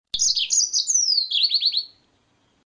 Green Singing Finch (Yellow-fronted Canary) - Serinus mozambicus
Only the (adult) cock sings, though hey may not sing year-round.
Song
Song clip (.mp3, .04 MB)
rGreenSinging.mp3